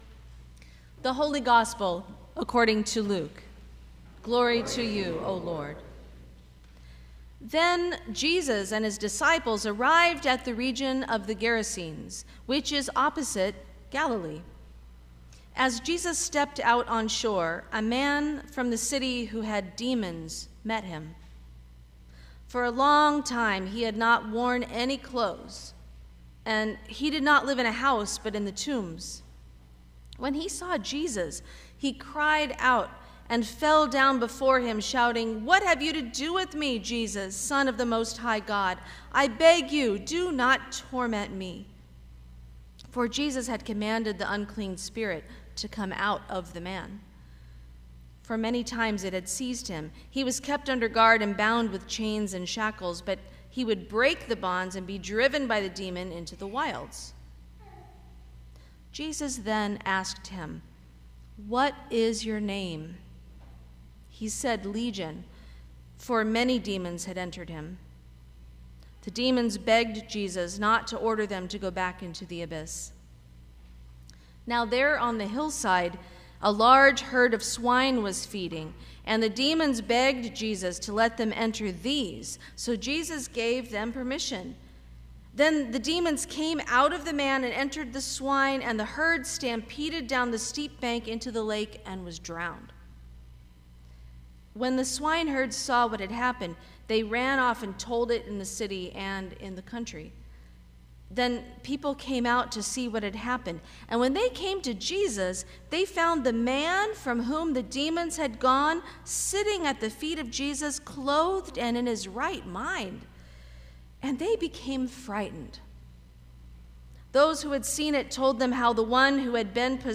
Sermon for the Second Sunday after Pentecost 2025